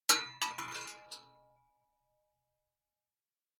Bullet Shell Sounds
rifle_metal_5.ogg